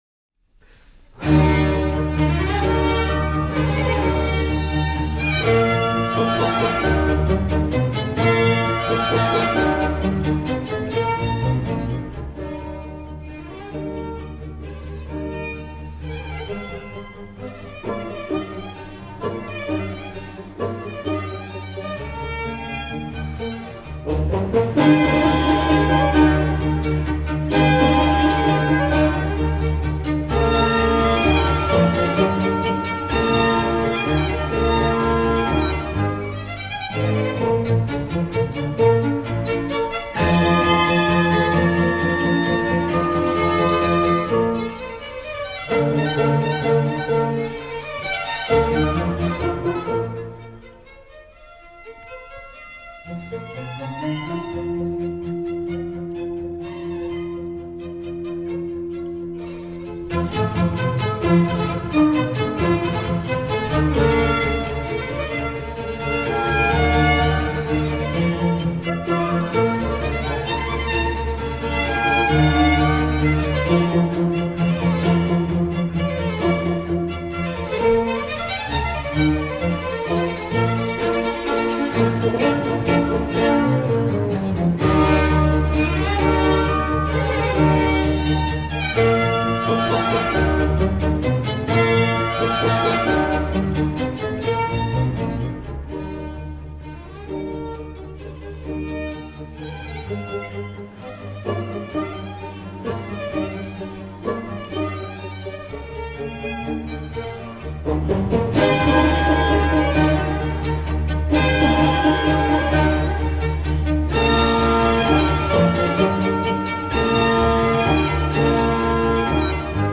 Трек размещён в разделе Зарубежная музыка / Классика.